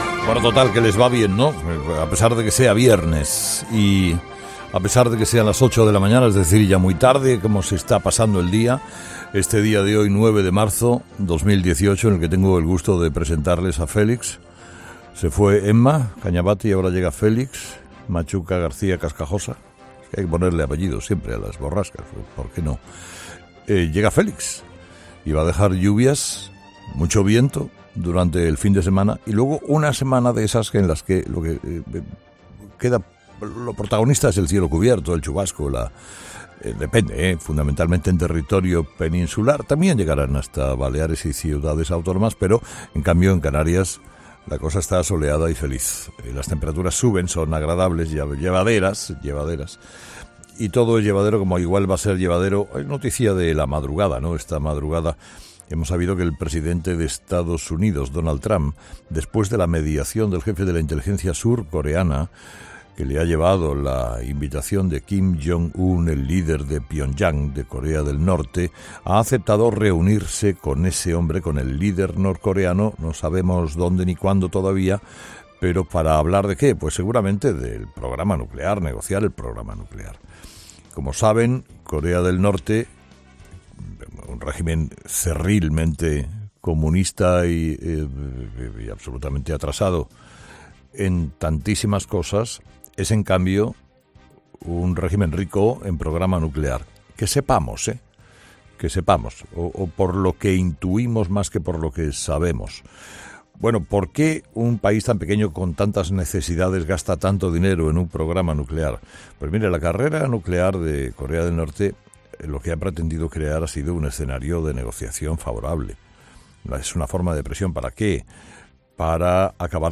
Monólogo de las 8 de Herrera 'Herrera en COPE'